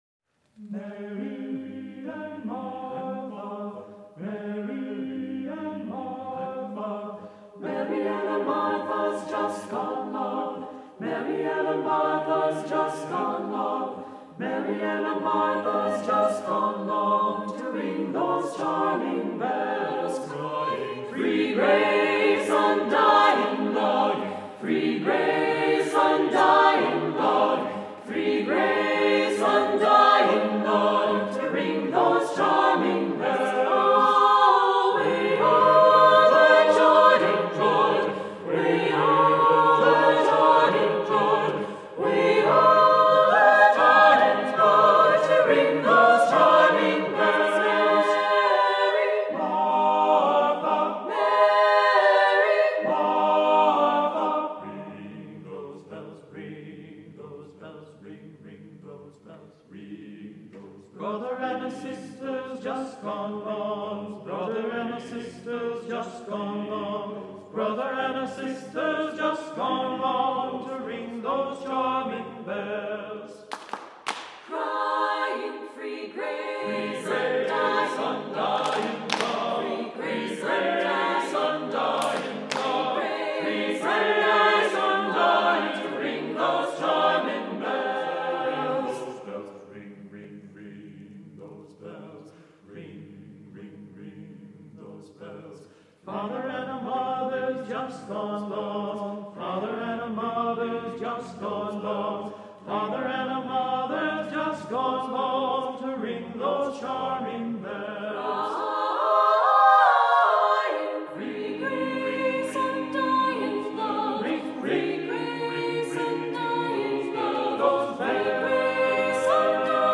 Voicing: SSAATTBB a cappella